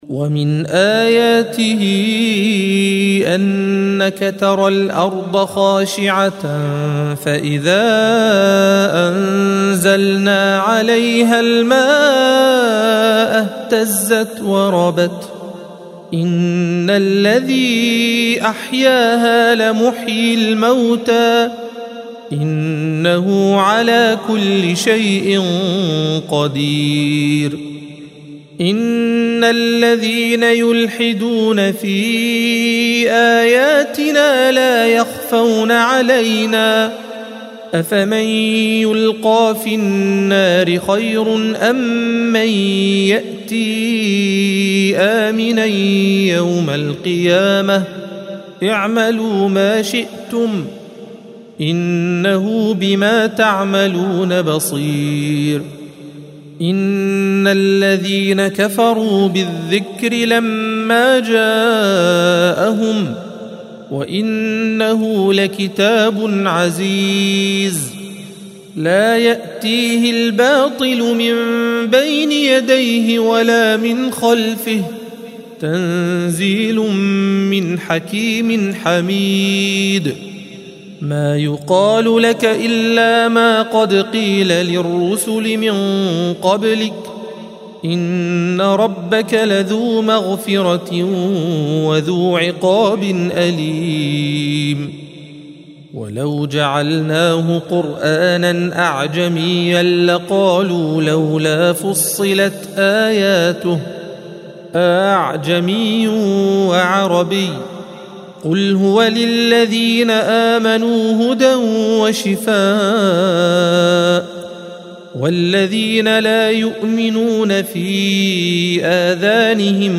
الصفحة 481 - القارئ